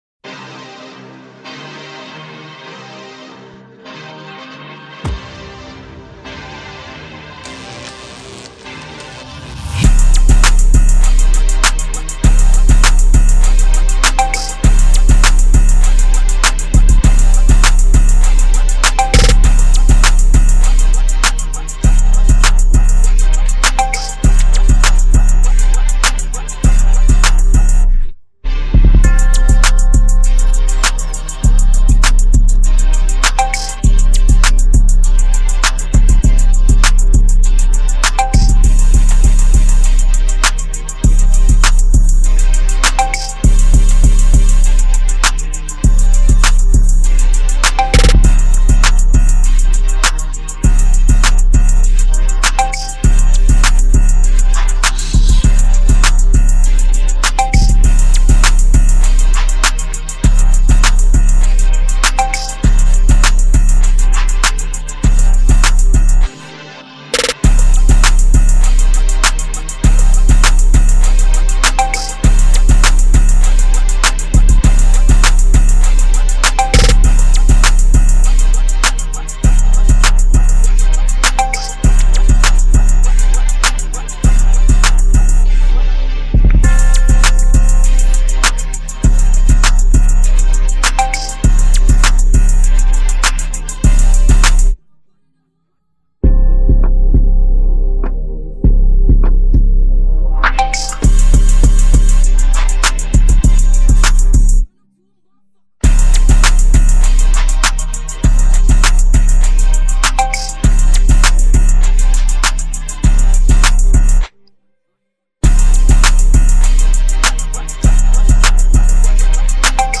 official instrumental